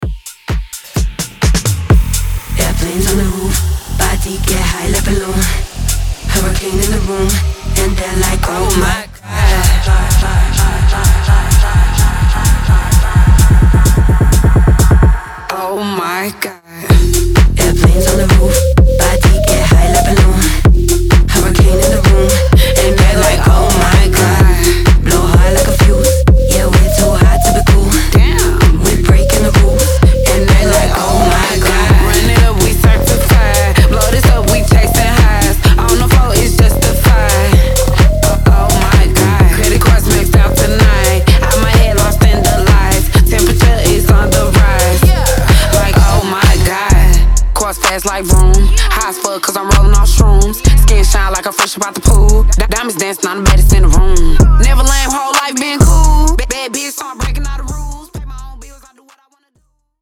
Latin music remixes